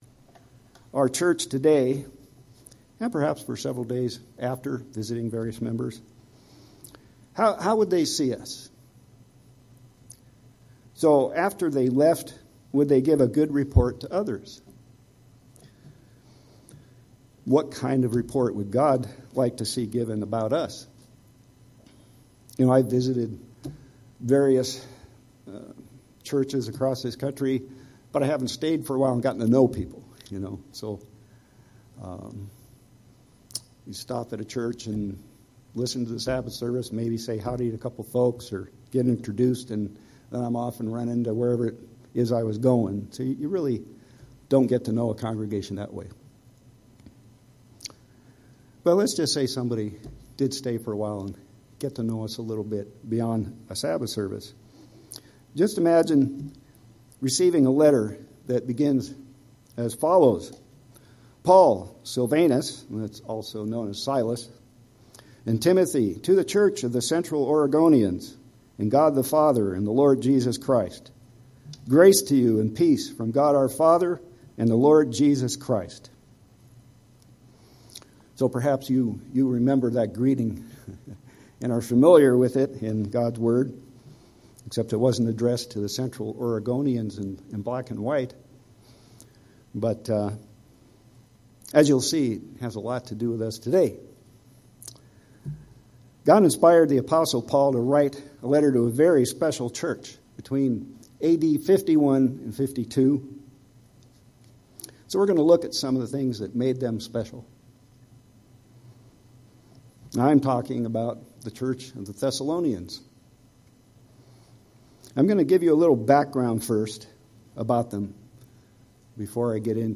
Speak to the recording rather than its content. Given in Central Oregon